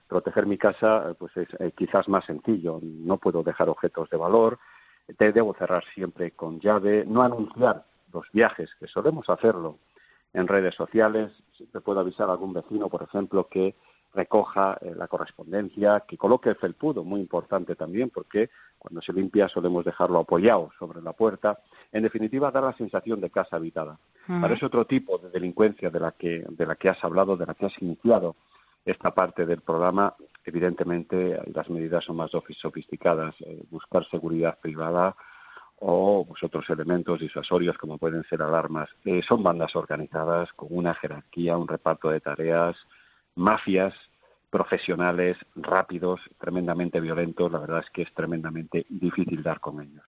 Un policía da las claves a tener en cuenta para disminuir las posibilidades de que te roben en casa